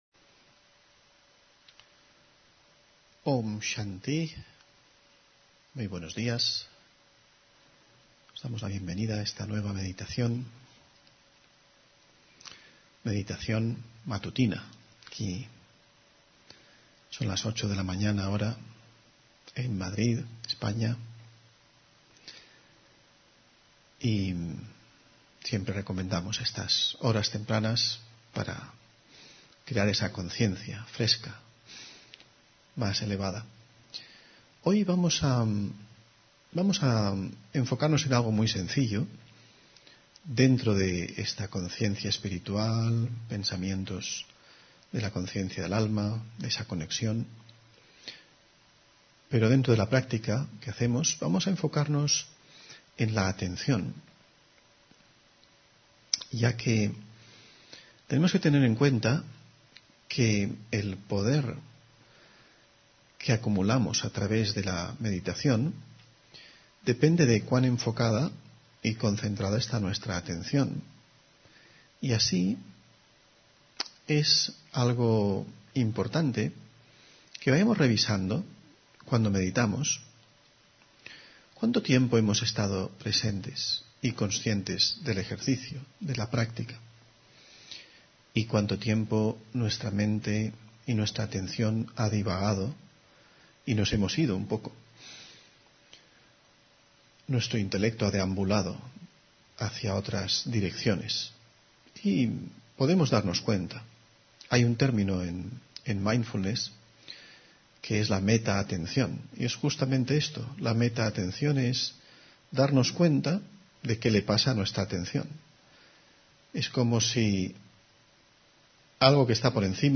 Meditación de la mañana: Fortalecer la atención